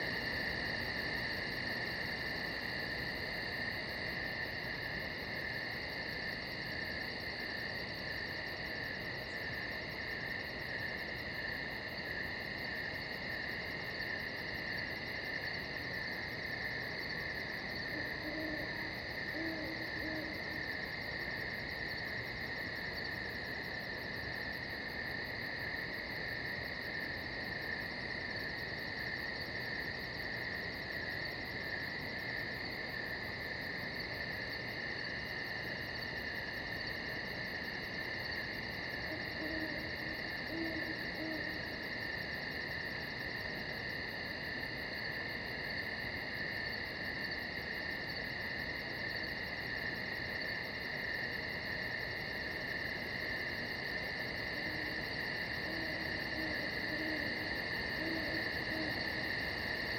BGS Loops / Interior Night
Inside Night.wav